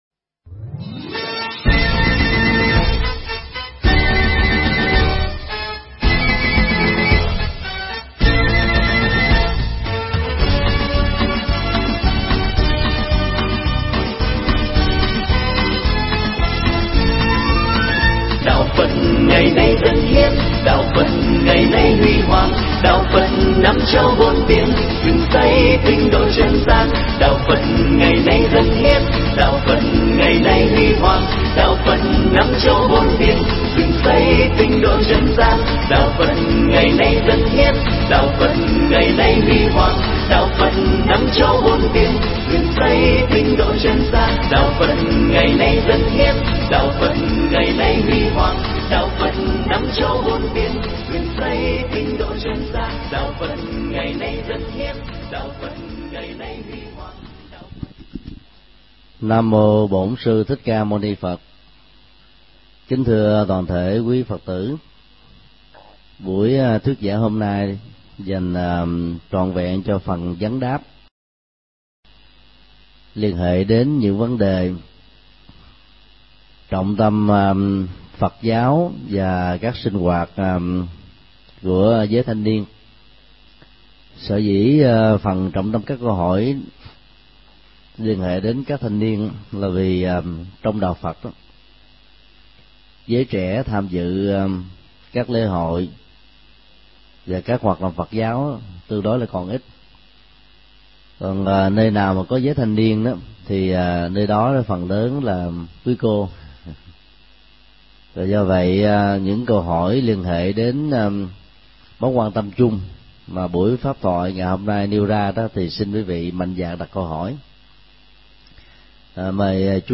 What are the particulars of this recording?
thuyết pháp trong chương trình vấn đáp Phật Giáo và các sinh hoạt của giới thanh niên tại chùa Giác Ngộ